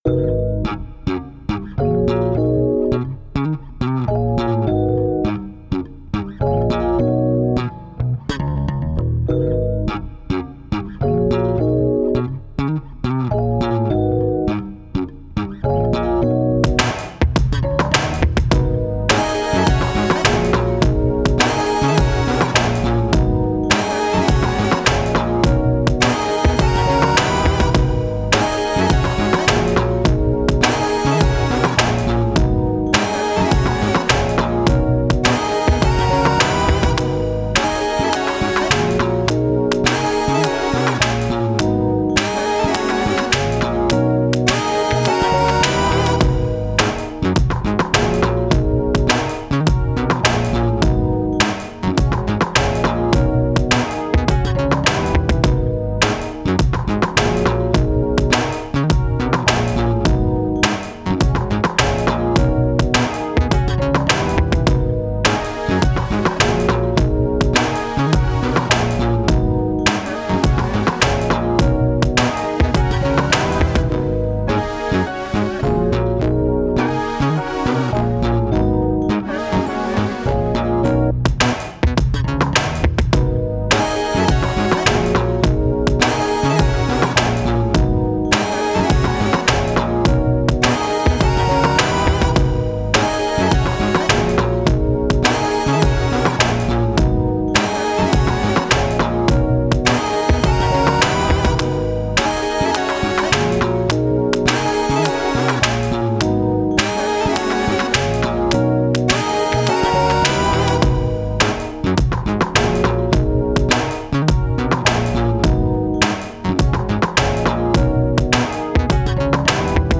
R&B, 80s
Eb Min